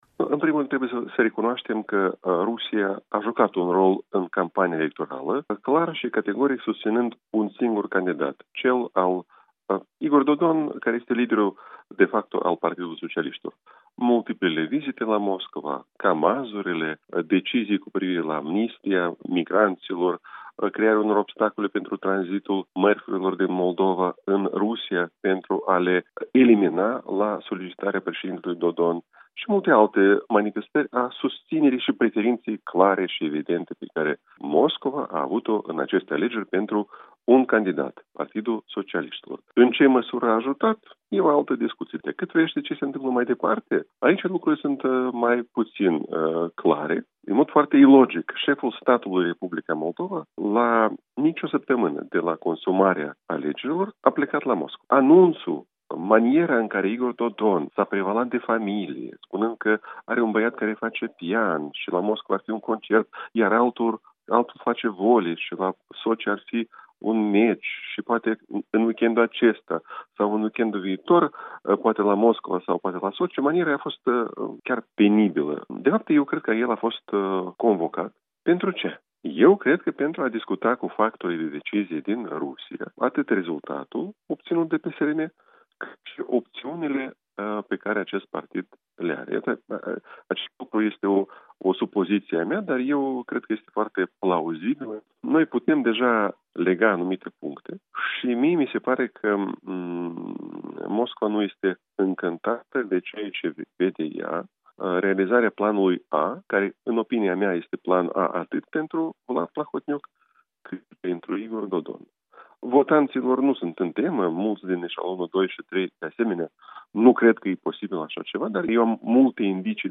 Interviu cu Andrei Popov